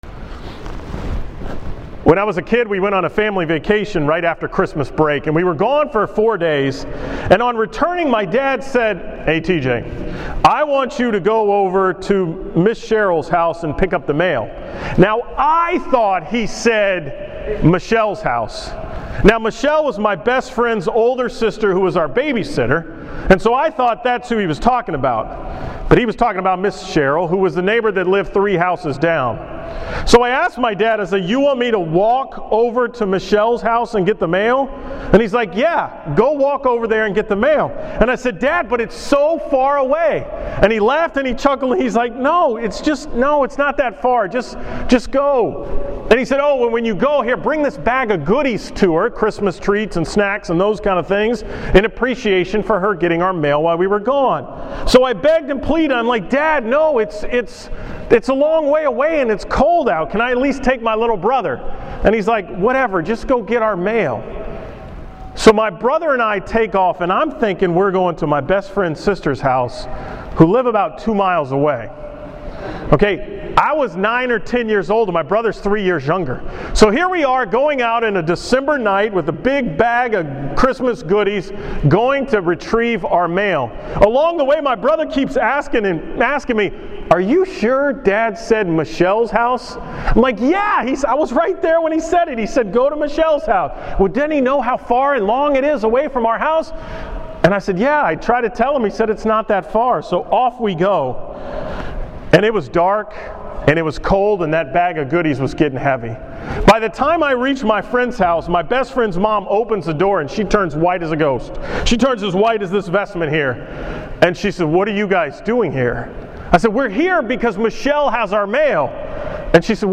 From the School Mass at Strake Jesuit on Friday, November 21, 2014